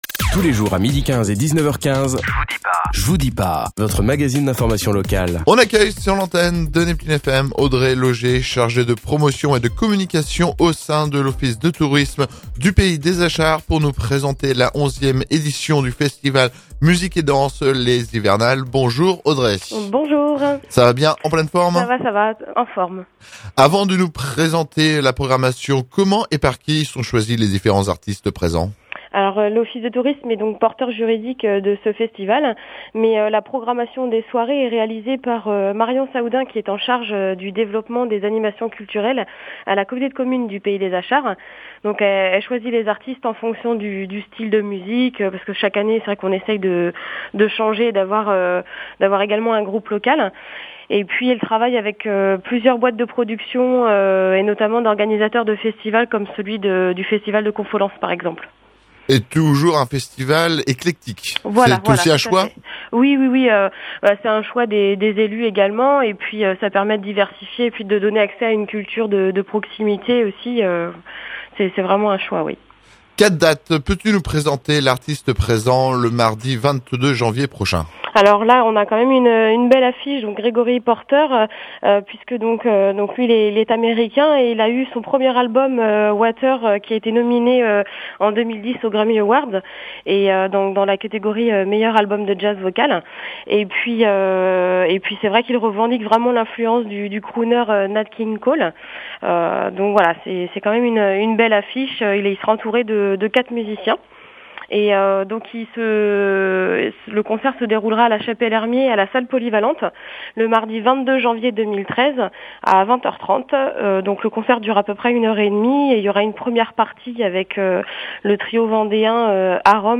Reportage / documentaire